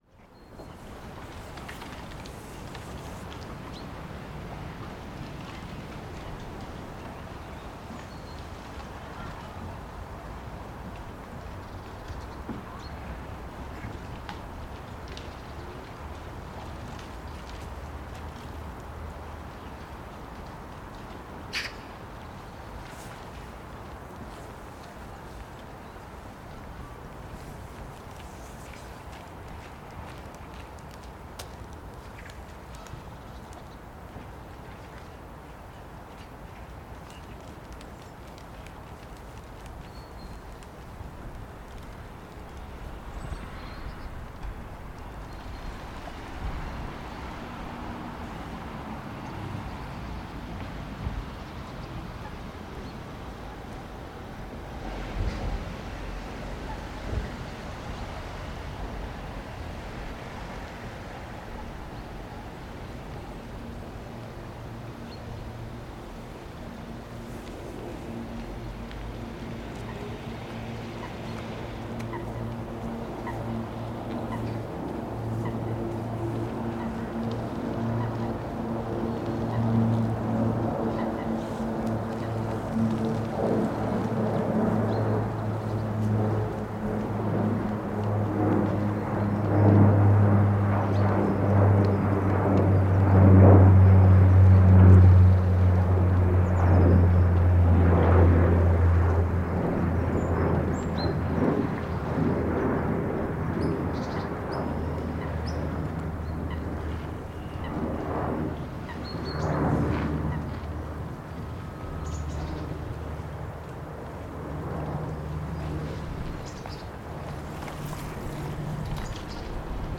I ventured outside this afternoon and made a recording with my Zoom H4 Essential, using the Zoom fluffy protector.
Oh and I added a fade-in and fade-out to make the entry and exit less abrupt. But other than that, there is no processing.